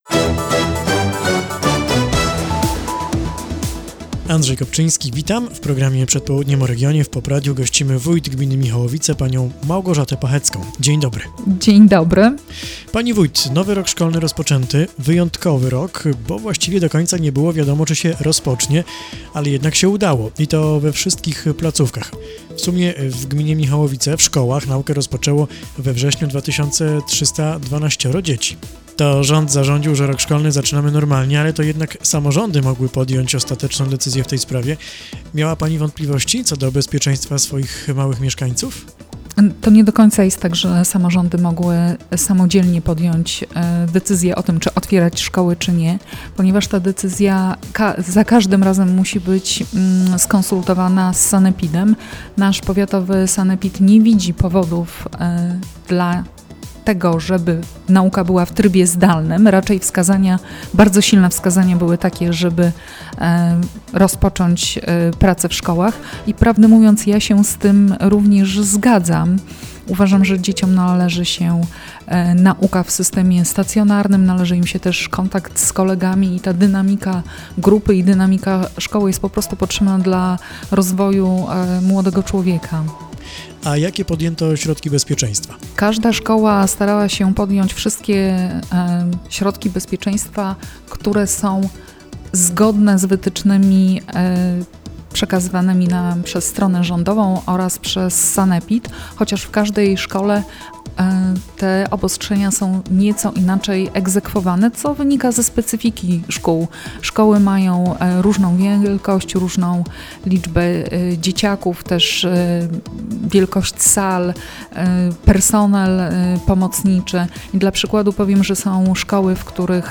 W dzisiejszej audycji Przed południem o regionie w POP Radiu Pruszków został wyemitowany wywiad z Małgorzatą Pachecką, wójt gminy Michałowice.
WYWIAD
wywiad_7wrzesnia.mp3